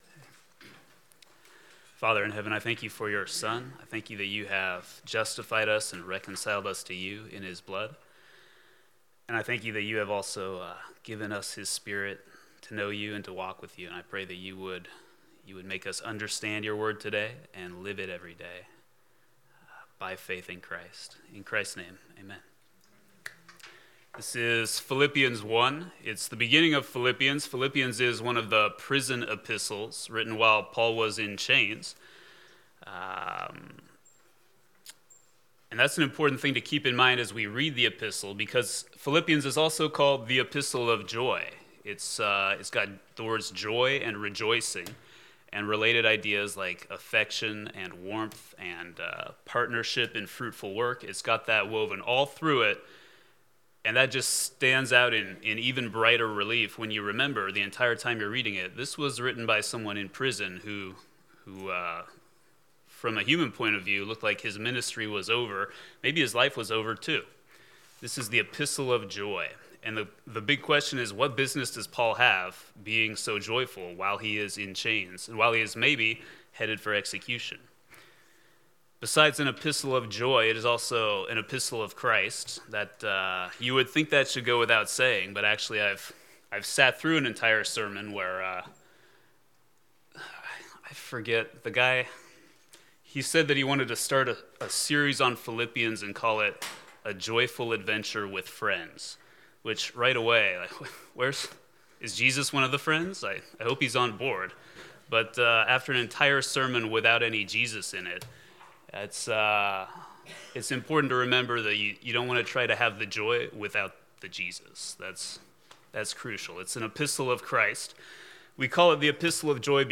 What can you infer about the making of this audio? Philippians 1 Service Type: Sunday School Download Files Bulletin « Lesson 6